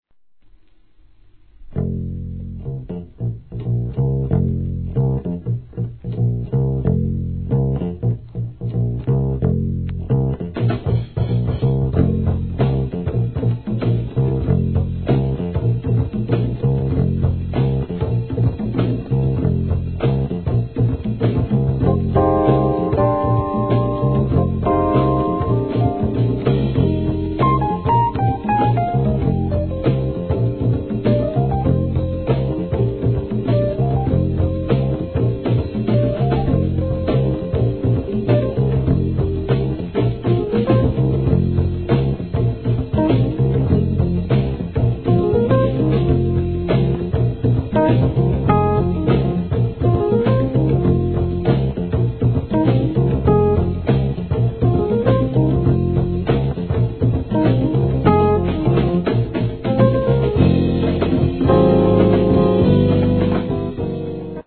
JAZZ (PIANO)